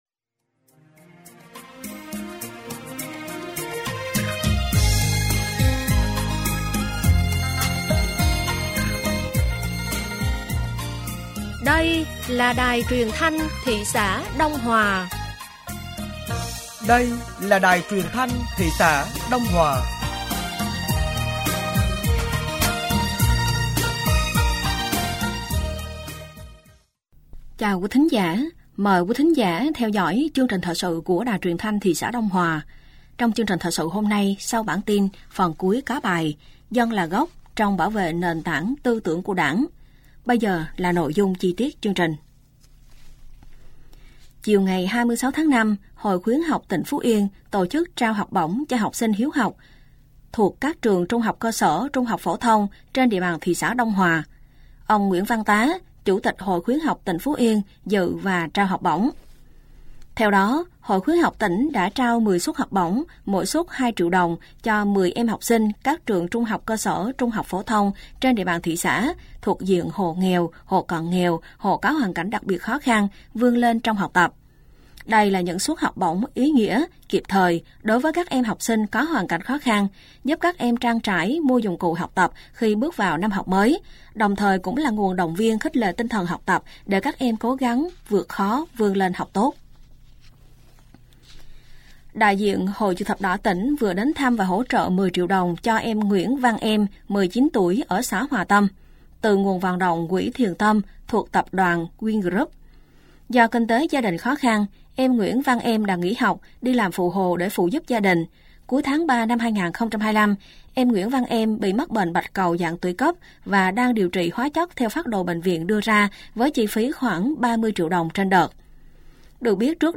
Thời sự tối ngày 26 và sáng ngày 27 tháng 5 năm 2025